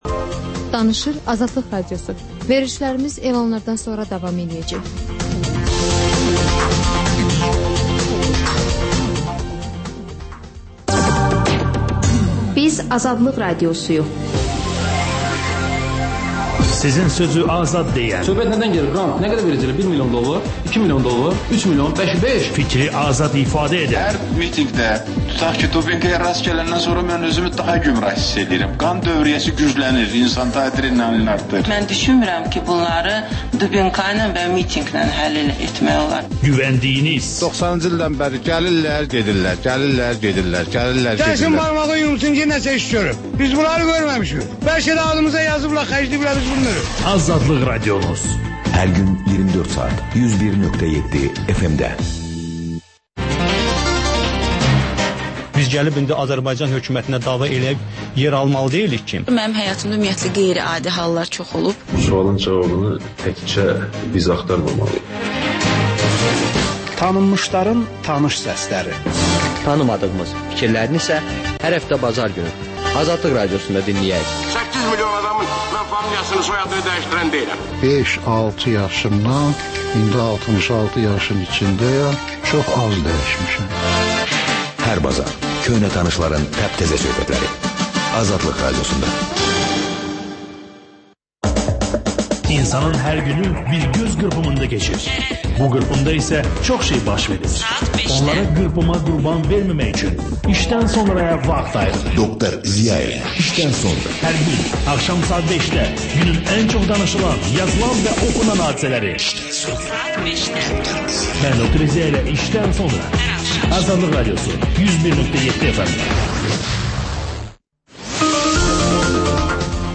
Xəbərlər, ardınca XÜSUSİ REPORTAJ rubrikası: Ölkənin ictimai-siyasi həyatına dair müxbir araşdırmaları. Sonda isə TANINMIŞLAR verilişi: Ölkənin tanınmış simalarıyla söhbət